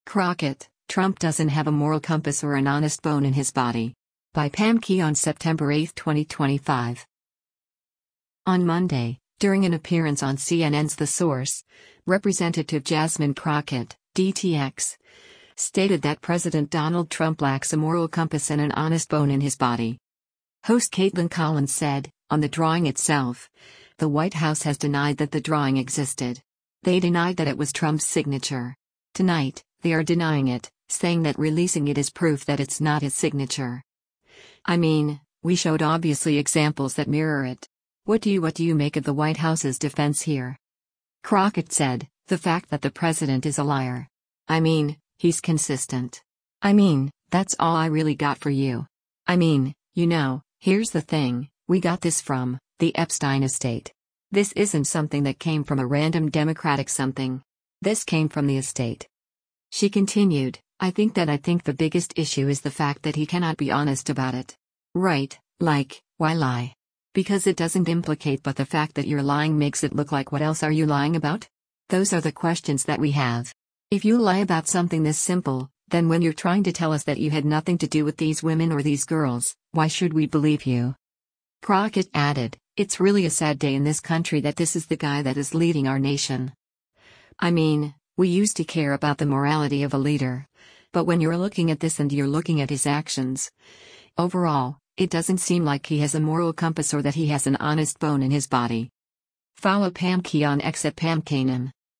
On Monday, during an appearance on CNN’s “The Source,” Rep. Jasmine Crockett (D-TX) stated that President Donald Trump lacks a “moral compass” and “an honest bone in his body.”